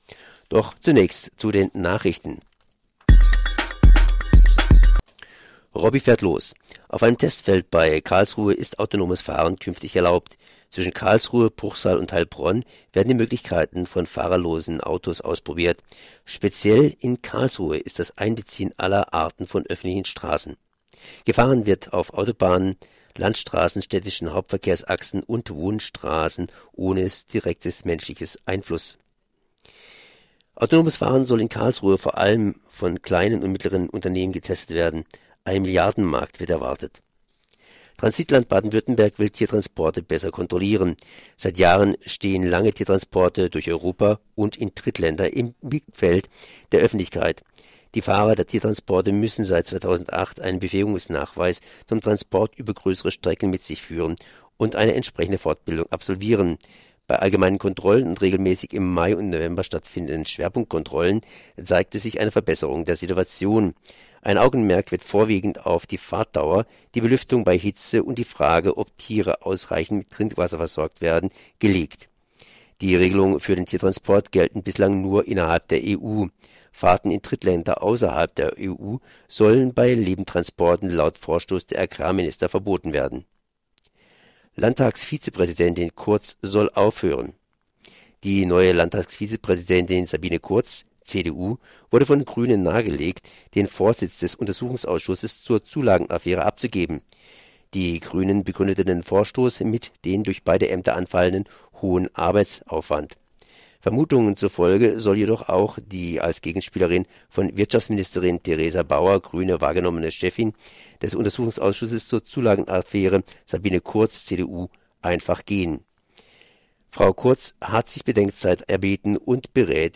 Nachrichten: